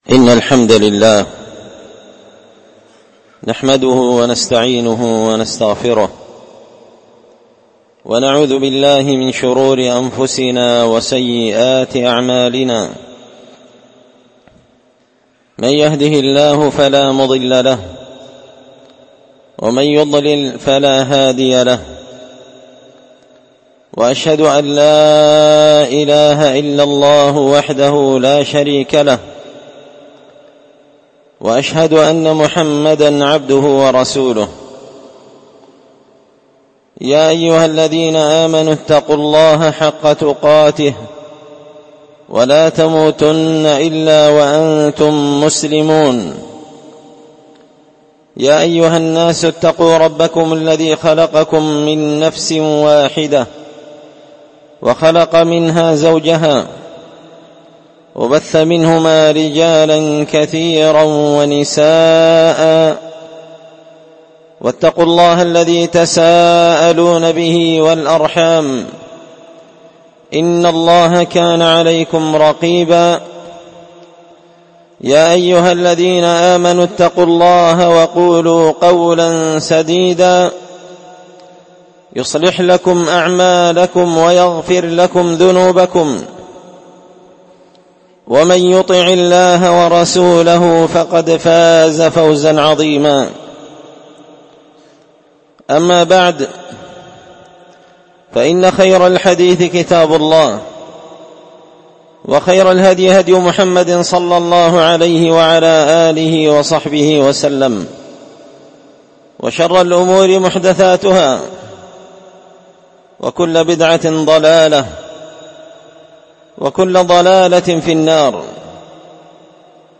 خطبة جمعة بعنوان حراسة الفضيلة وحماية المجتمع من الرذيلة